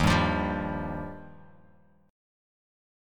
Ebadd9 chord